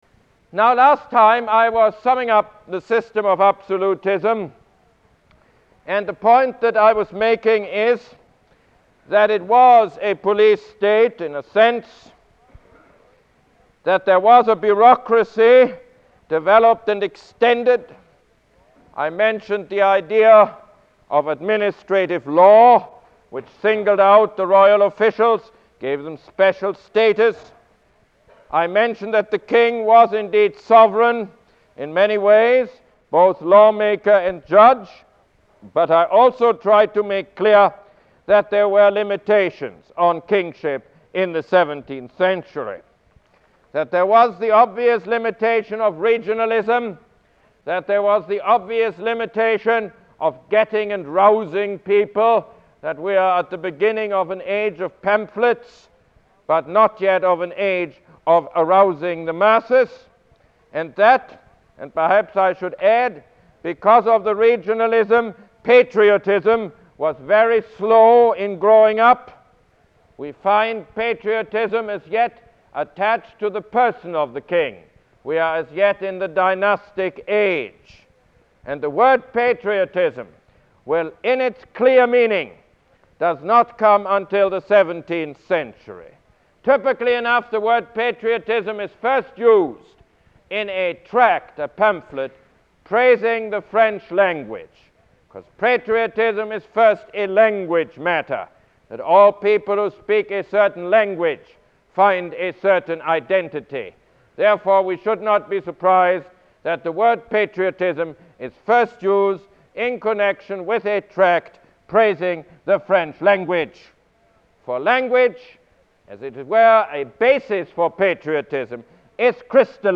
Lecture #13 - The System of Absolution